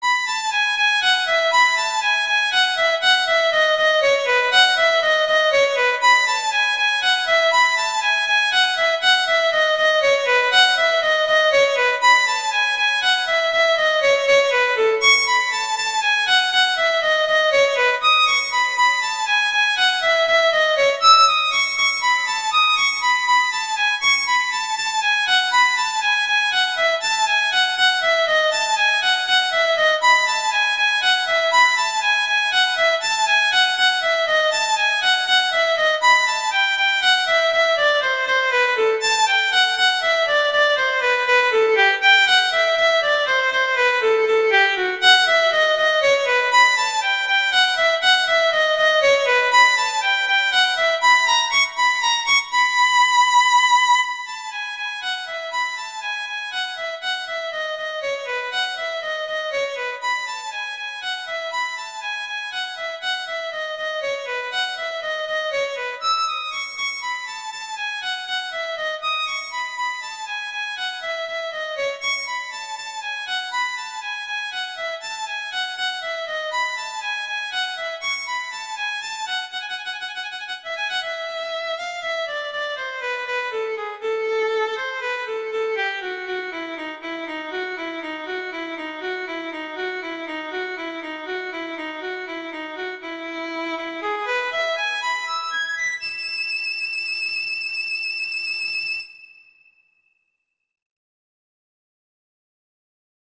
classical, instructional